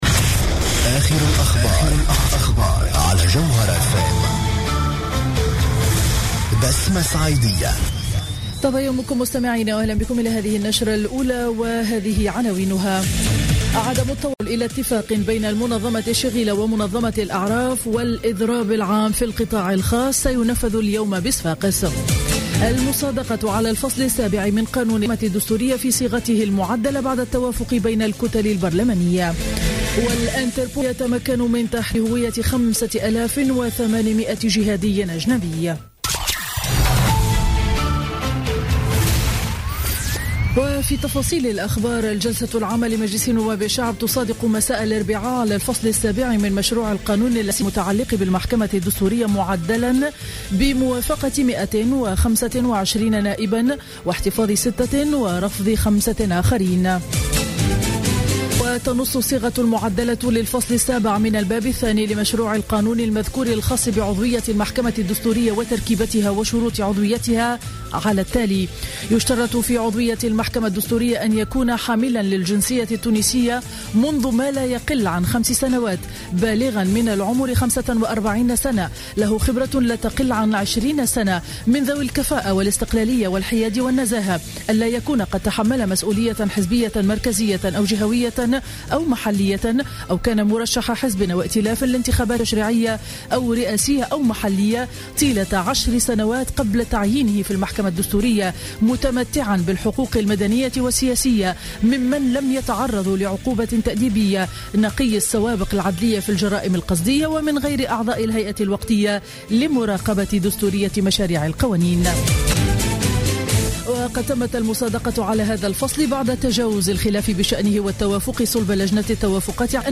نشرة أخبار السابعة صباحا ليوم الخميس 19 نوفمبر 2015